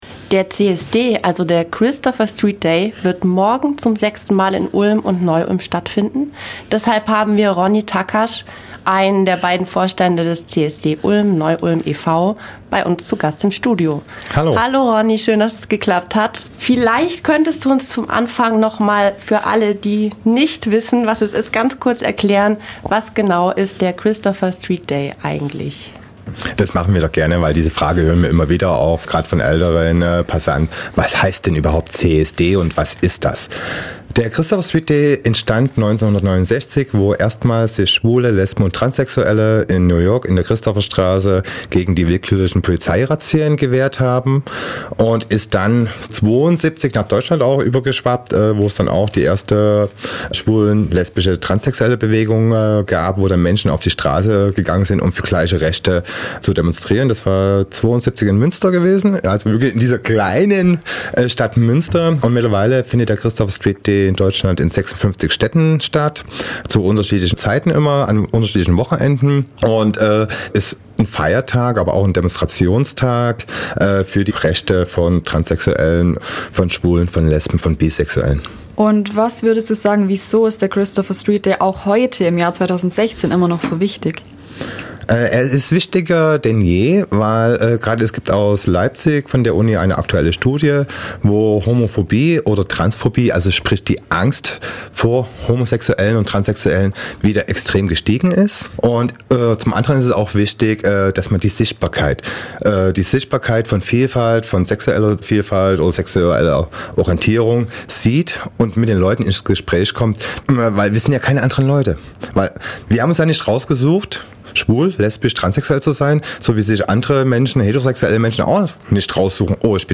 im Gespräch über den Christopher Street Day